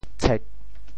嘁 部首拼音 部首 口 总笔划 14 部外笔划 11 普通话 qī 潮州发音 潮州 cêg4 文 中文解释 嘁〈象〉 形容低语声或轻细的声音。
tshek4.mp3